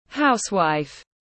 Housewife /ˈhaʊs.waɪf/